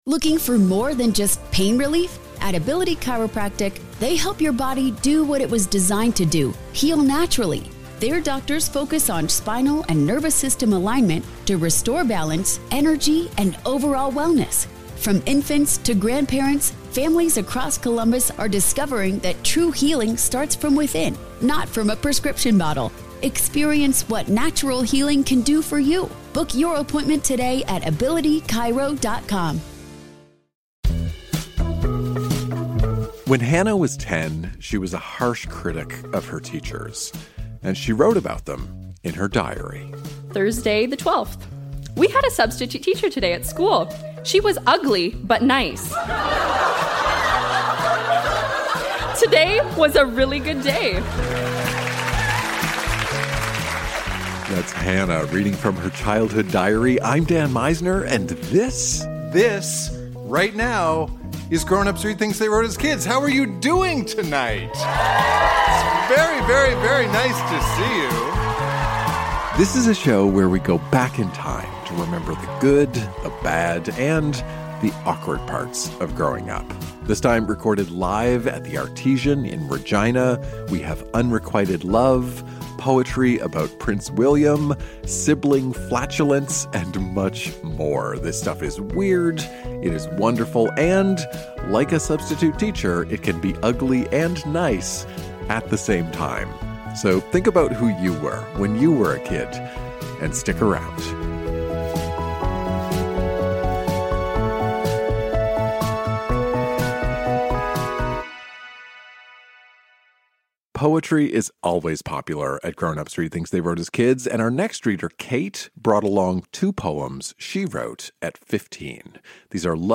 Recorded live at The Artesian in Regina.